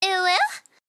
UwU Female Sound
Uwu-Anime-Sound-Effects-128-kbps-mp3cut.net_-1.mp3